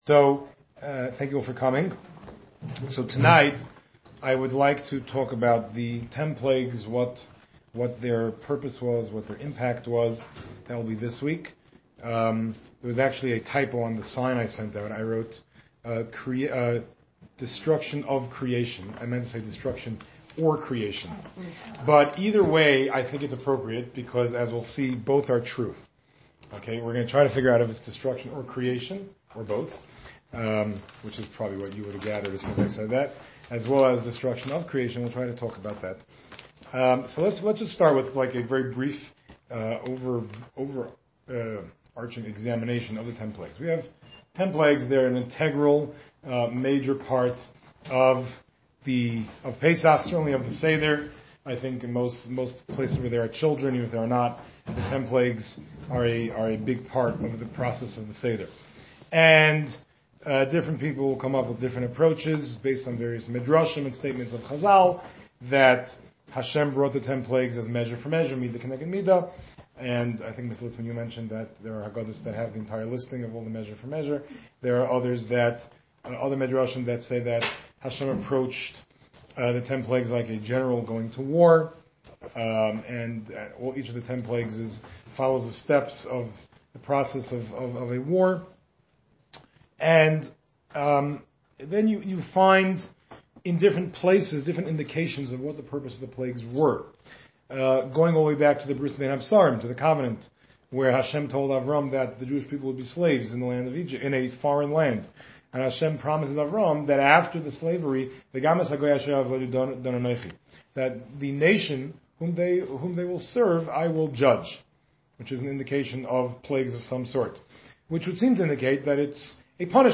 More than 50 women attended the classes.